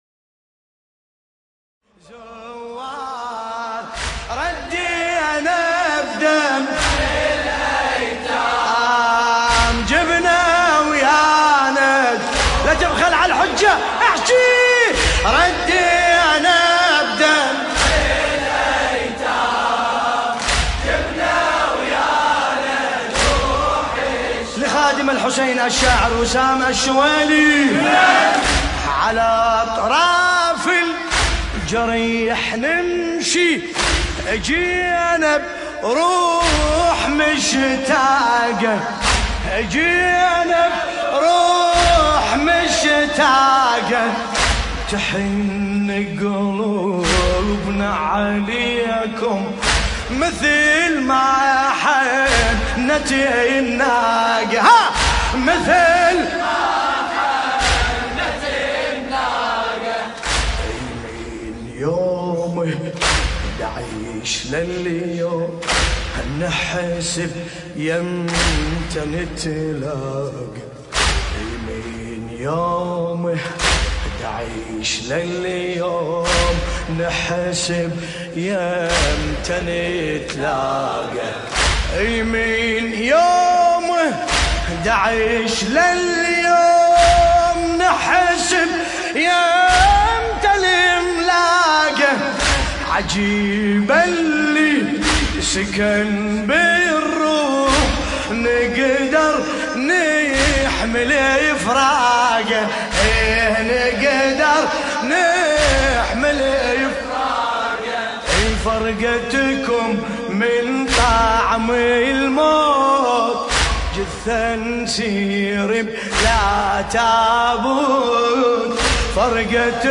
ملف صوتی انمركم زوار بصوت باسم الكربلائي
الرادود : الحاج ملا باسم الكربلائي
حسينية السيدة زينب (ع) مطرح ـ عمان